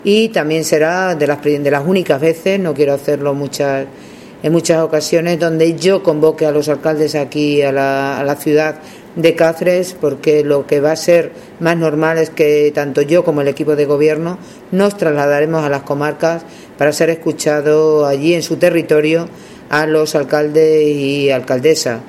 CORTES DE VOZ
AUDIO_CHARO_CORDERO_REUNIxN_ALCALDES_PROVINCIA.mp3